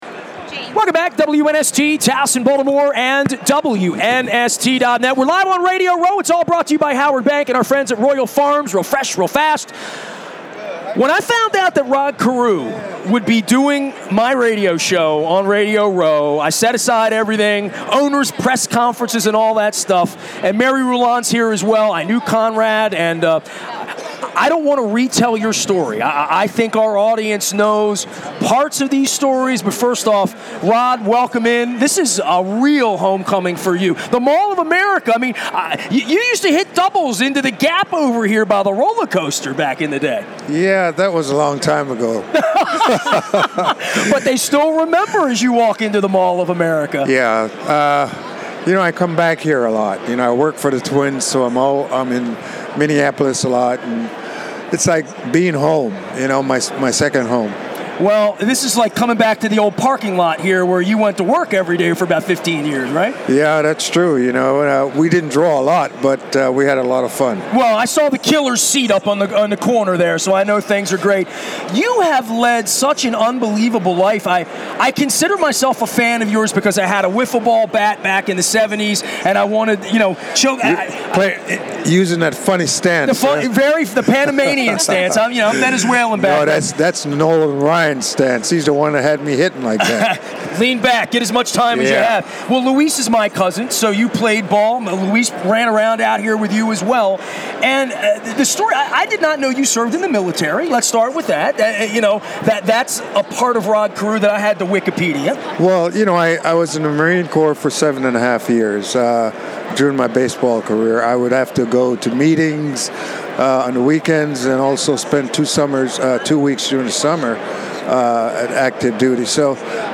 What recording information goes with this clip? on Radio Row at Super Bowl 52